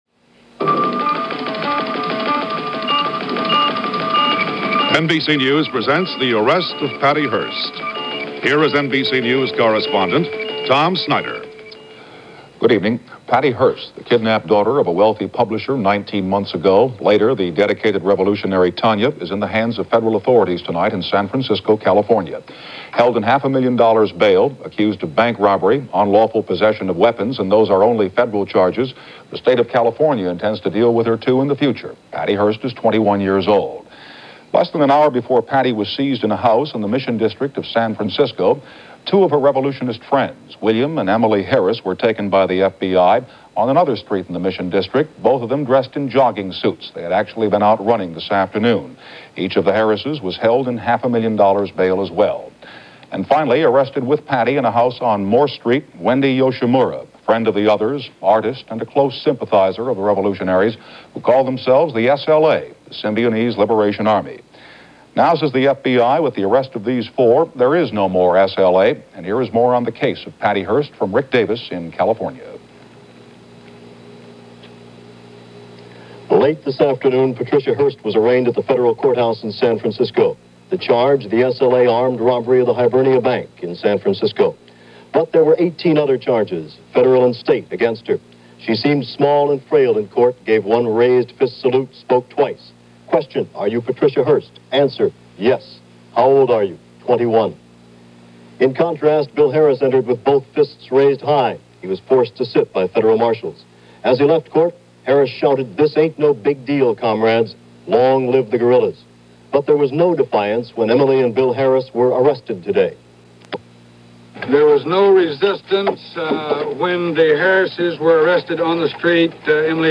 The Arrest Of Patty Hearst - September 18, 1975 - News reports on the arrest of Patty Hearst, earlier in the day via NBC News.